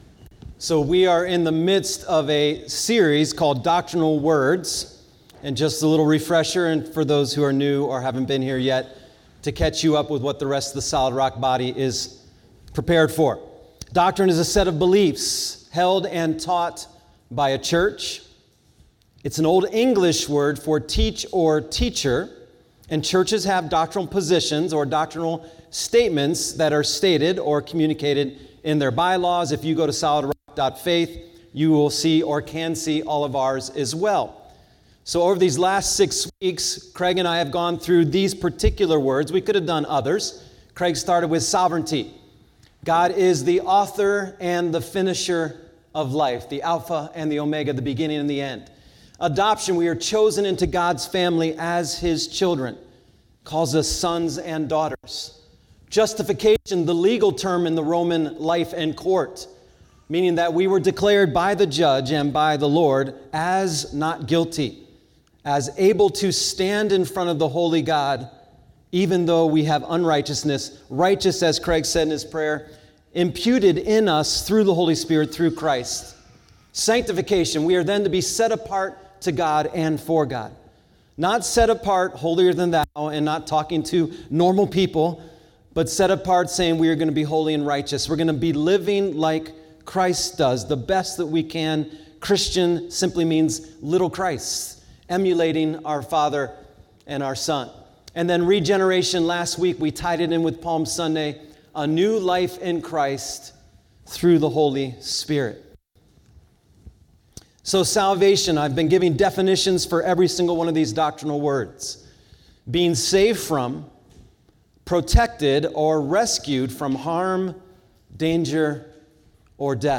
Sermons | Solid Rock Church